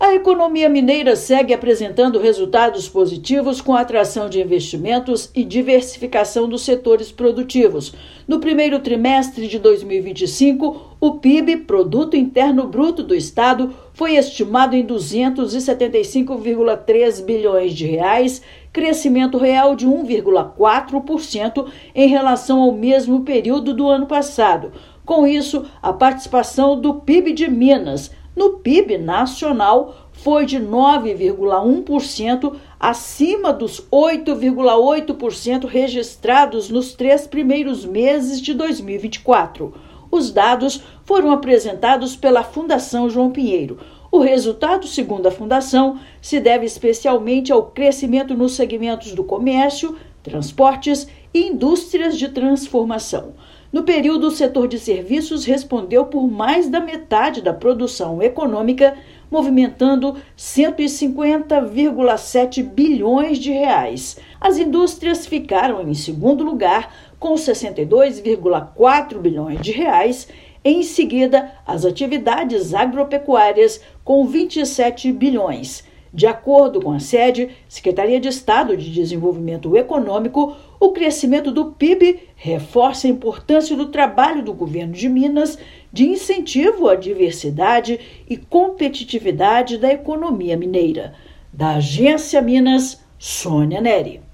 Com expansão das indústrias de transformação, transportes e comércio, o Produto Interno Bruto (PIB) nominal do estado foi estimado em R$ 275,3 bilhões. Ouça matéria de rádio.